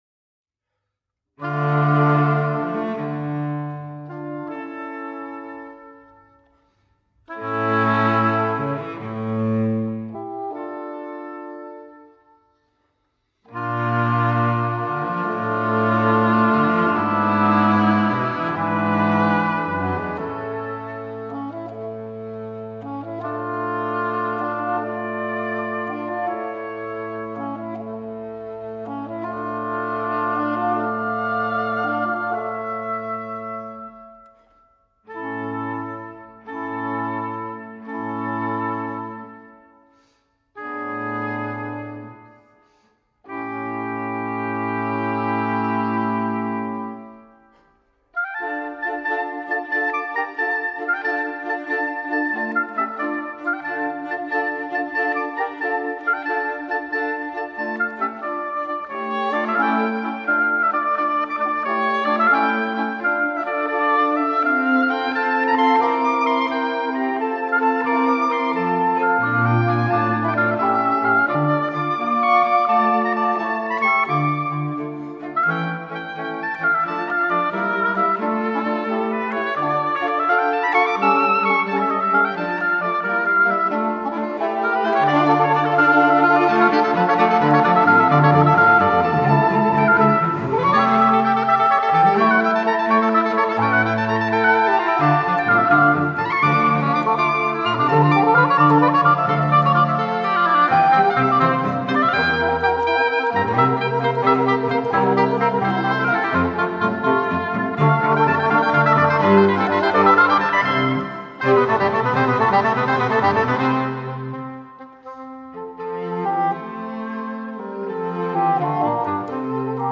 Adagio-Allegro